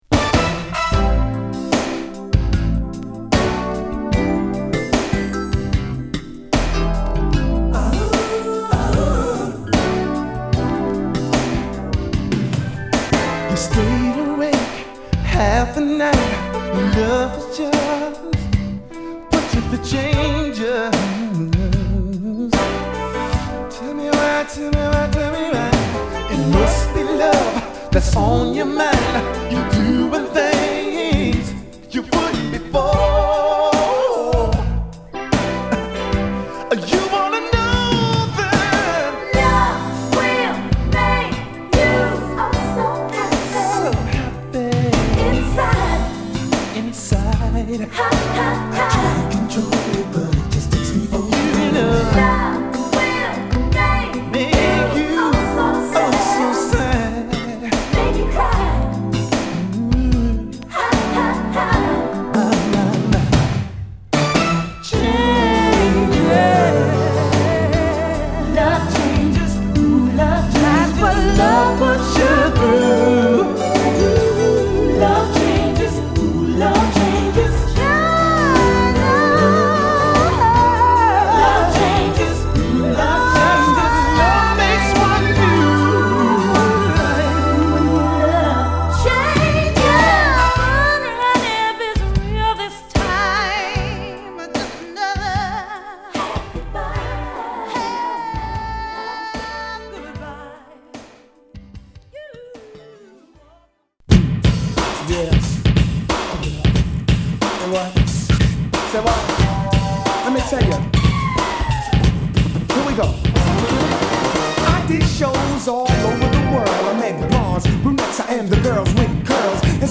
生演奏+打ち込みで90s R&Bの基板となるサウンドを披露。